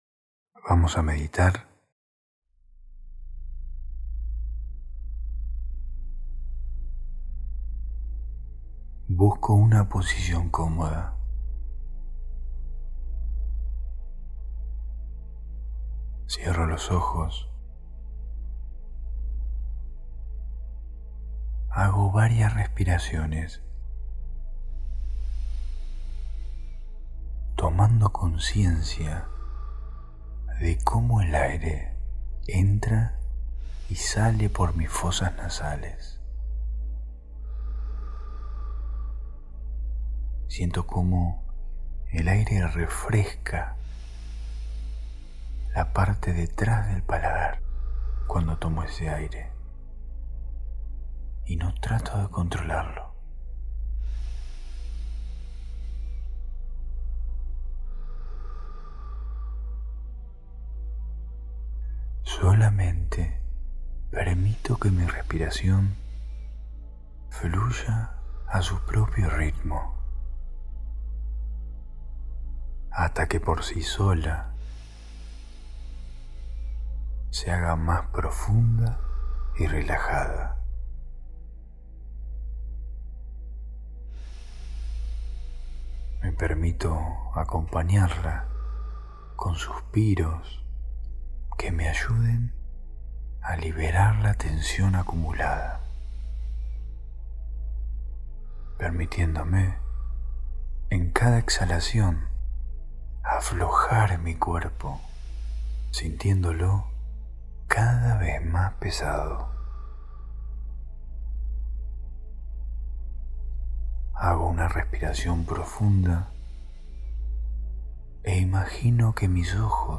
Meditación para calmar la ansiedad